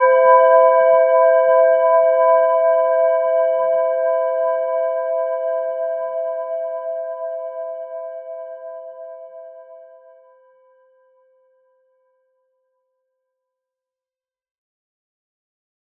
Gentle-Metallic-2-E5-mf.wav